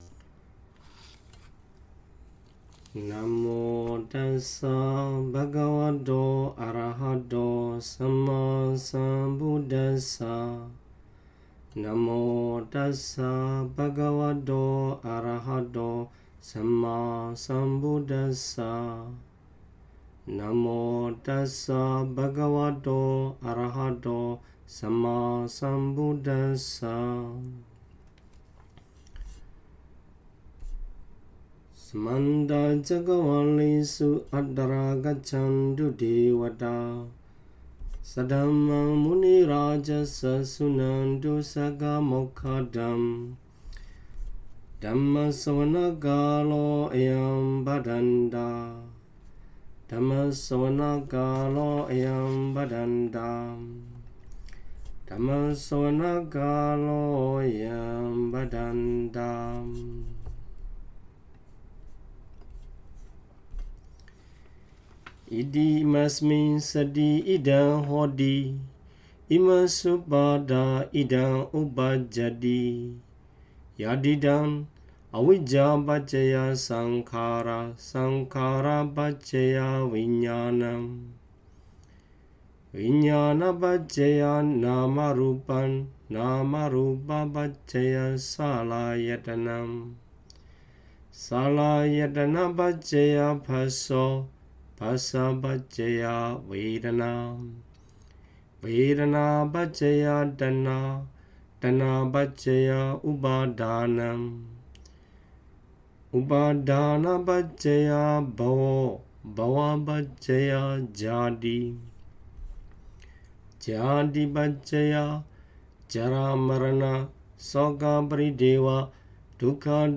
Intensive Satipaṭṭhāna Meditation Retreat Chanting